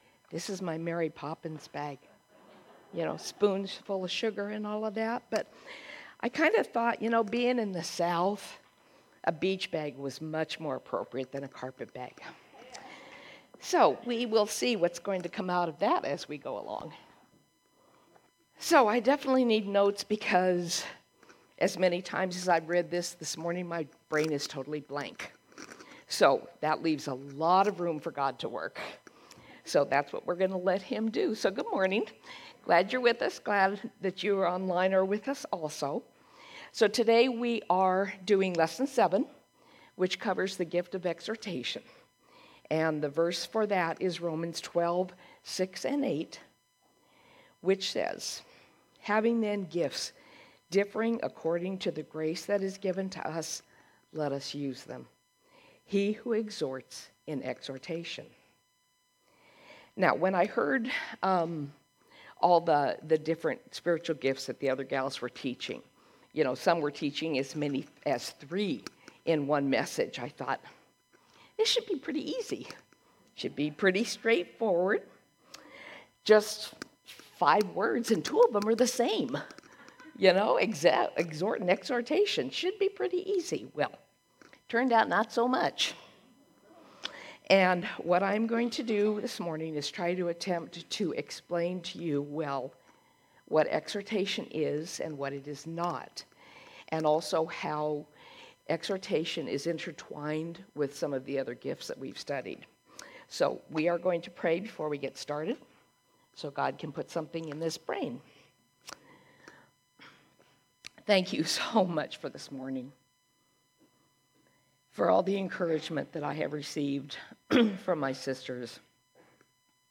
A message from the series "Women of the Word."